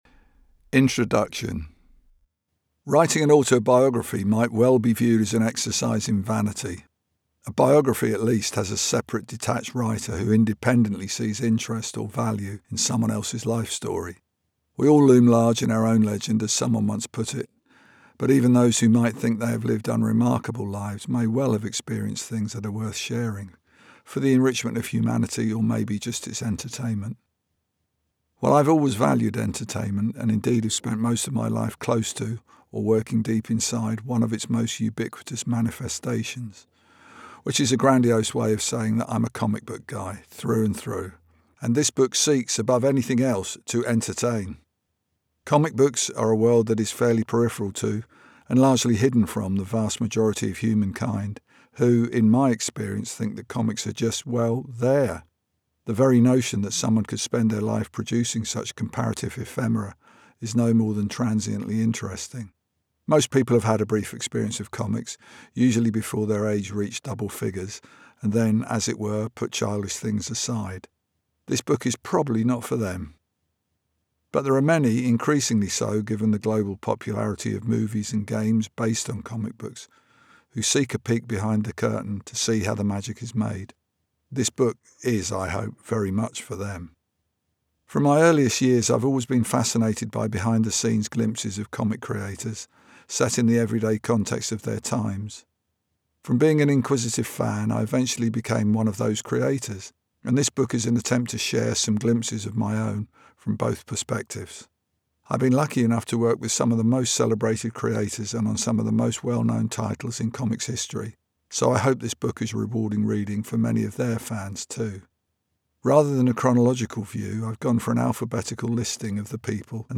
Comic artist and Watchmen co-creator Dave Gibbons reads Confabulation, his comprehensive and personal journey as a comic artist, spanning his early years copying Superman and Batman comics, to co-creating one of the bestselling graphic novels of all-time.